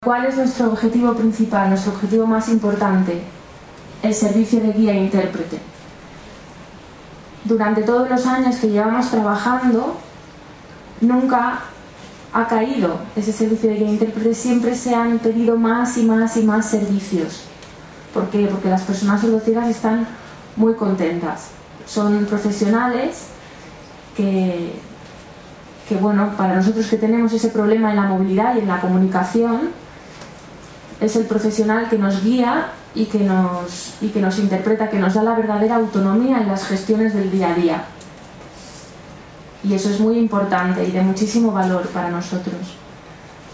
El acto central se celebró en el Complejo Deportivo y Cultural de la ONCE en Madrid, con el lema “Caminando sin barreras”.